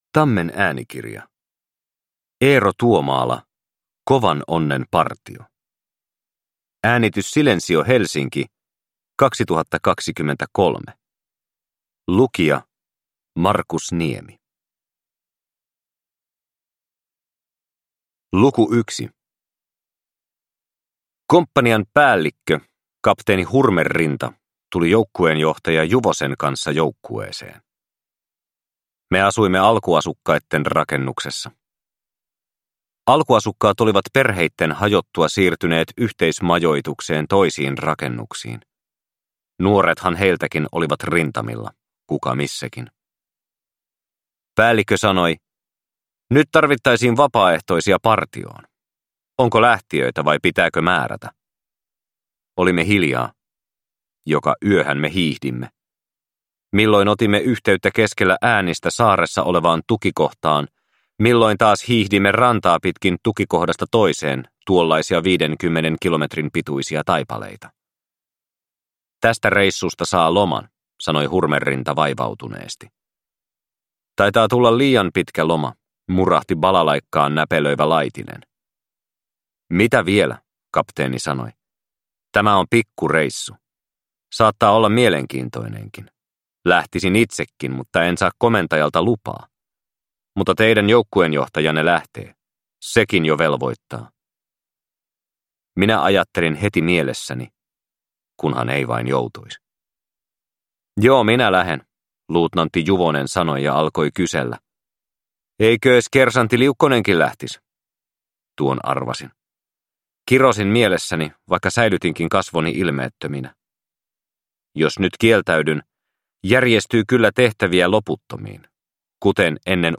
Kovan onnen partio – Ljudbok – Laddas ner